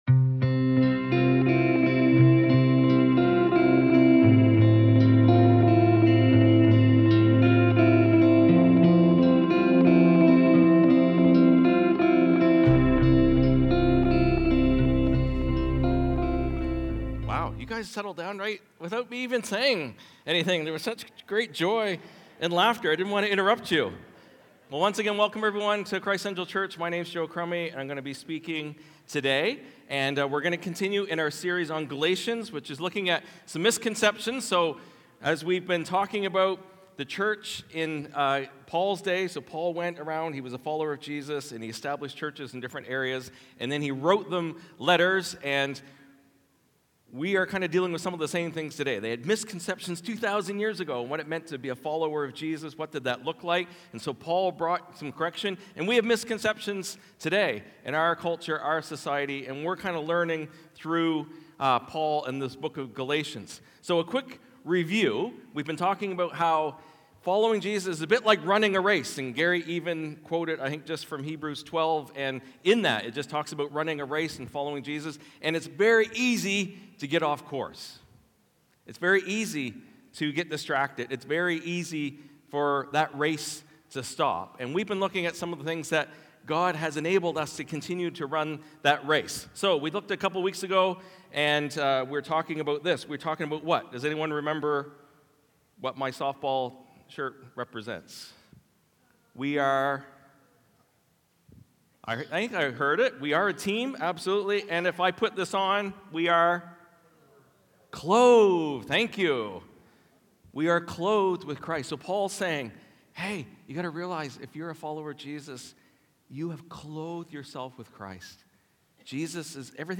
Sermons | Christ Central Church